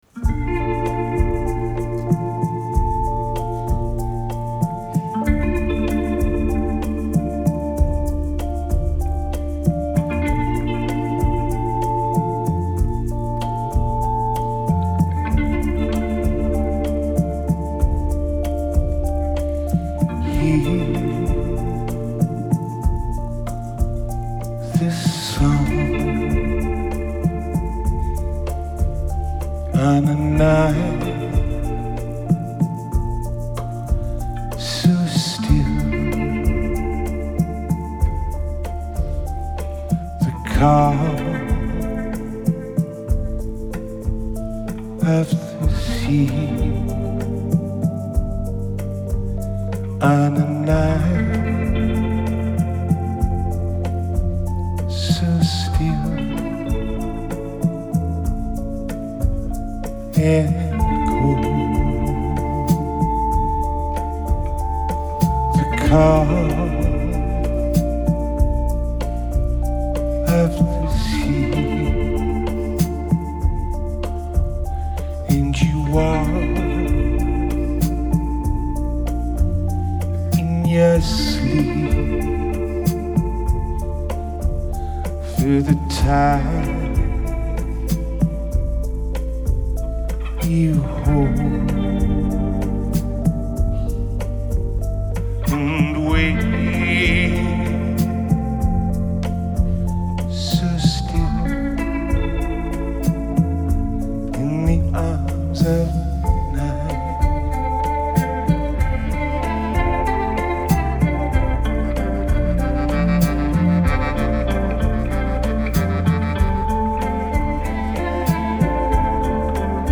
Genre: Alternative, Indie Rock, Chamber Pop